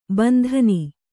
♪ bandhani